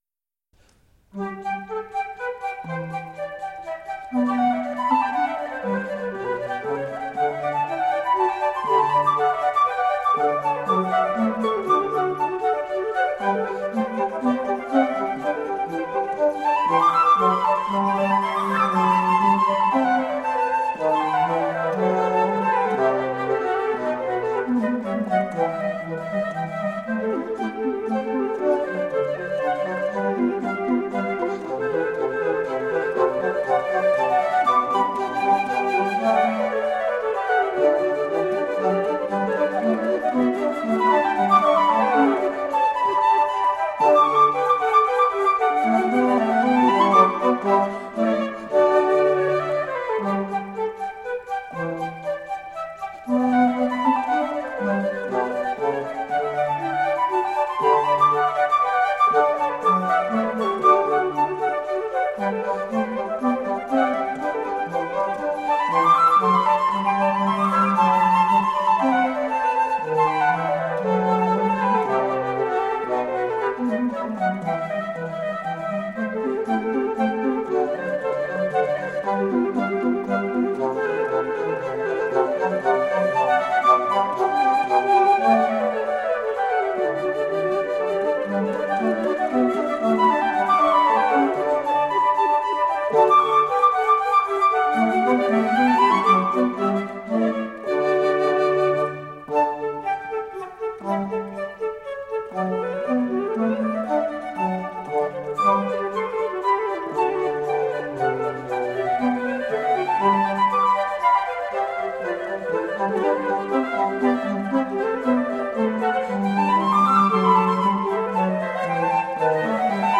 Flautists with finesse, intelligence and grooves.
flute ensemble
recorded live in Hakodate City Art Hall on 22 March 1999
Classical, Baroque, Impressionism, Instrumental
Flute